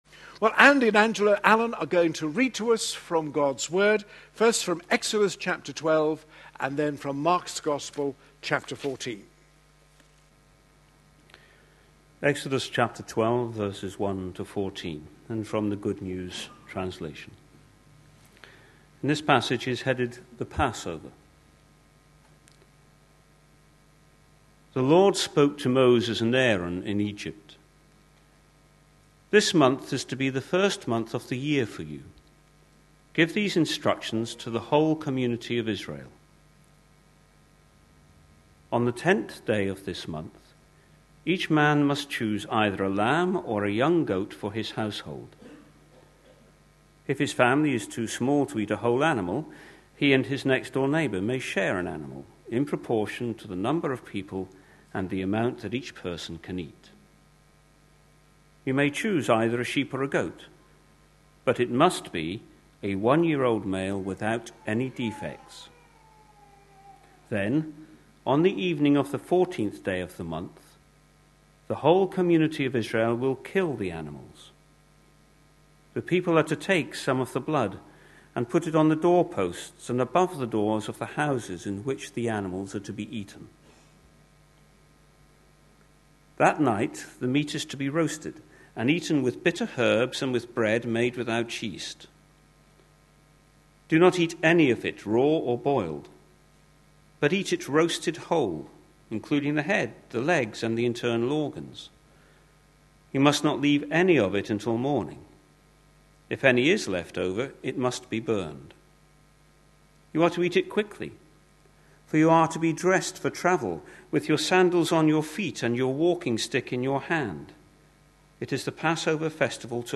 A sermon preached on 9th June, 2013, as part of our Four `Words' from 1 Corinthians. series.